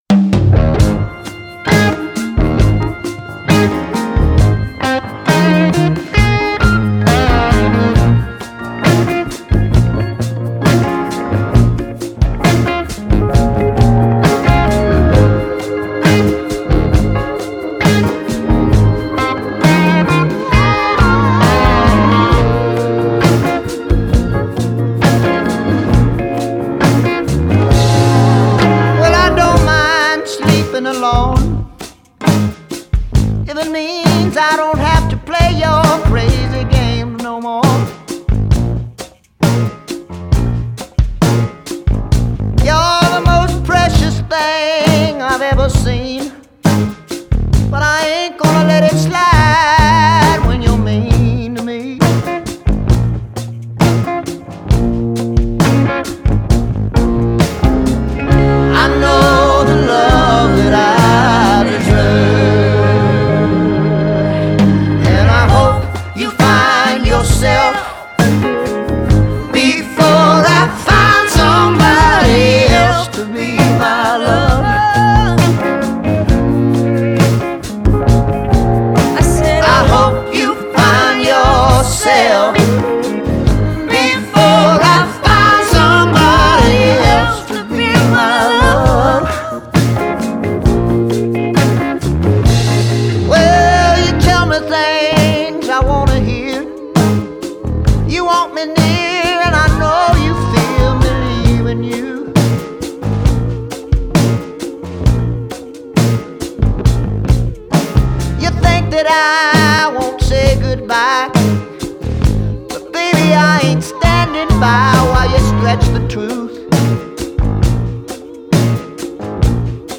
roots rocker